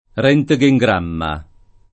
rHntgejgr#mma o rHjgejgr#mma; meno bene, alla ted., r£ntgejgr#mma] (meno com. roentgengramma [id.]) s. m. (med.); pl. -mi — termine poco it. (e del resto non molto usato) per radiogramma